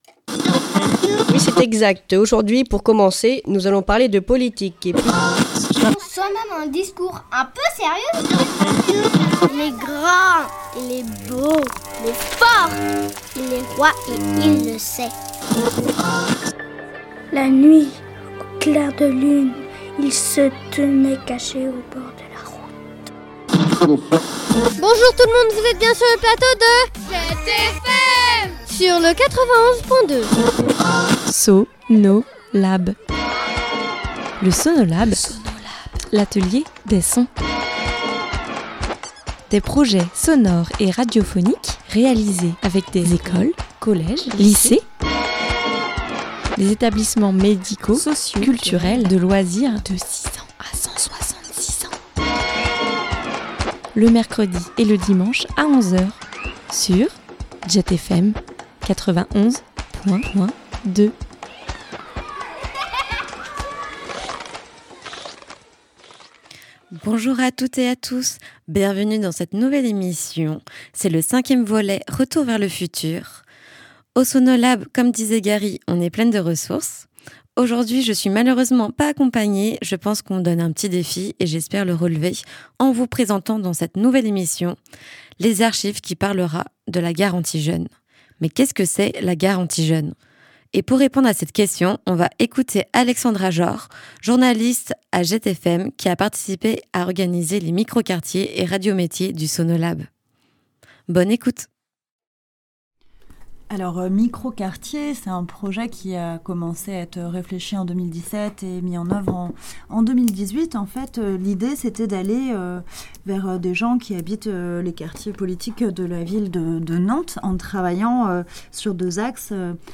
Ils vont faire des chroniques sur des formations qui les attirent comme le relais atlantique (réemploi de vêtements) et les débouchés dans la logistique.